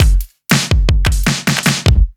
Index of /musicradar/off-the-grid-samples/110bpm
OTG_Kit7_Wonk_110b.wav